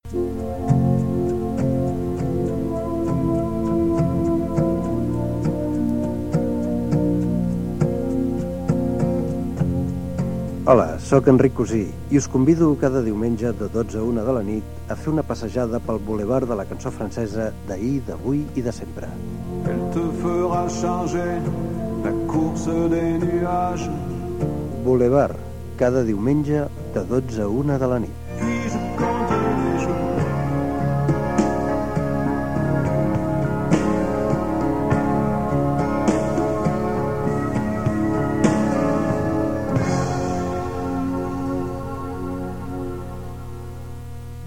Promoció del programa dedicat a la música francesa
FM